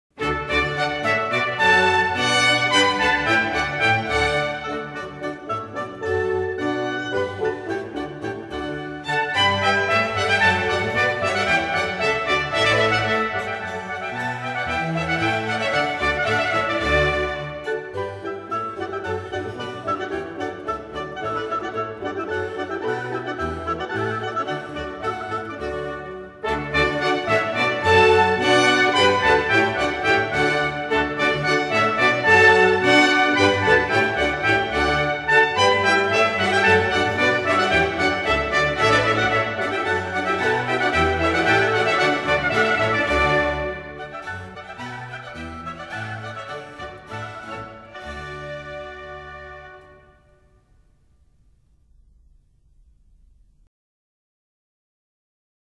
布列舞曲
这是一段轻盈欢快的舞曲，美丽来自妙趣横生、变化多端的节奏。